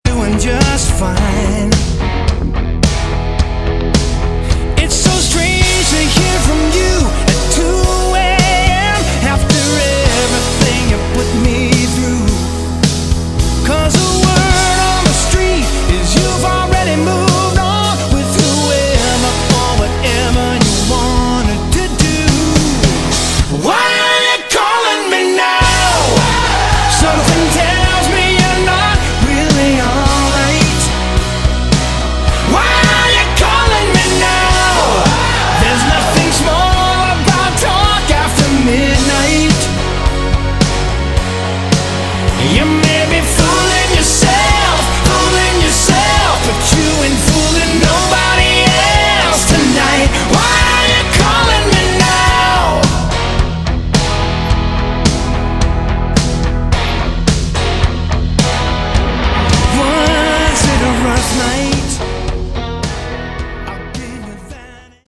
Category: AOR / Melodic Rock
vocals, keyboards, guitars